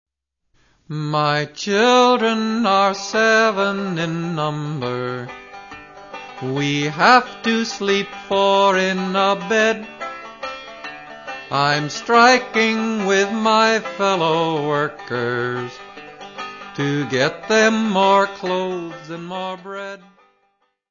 Music Category/Genre:  World and Traditional Music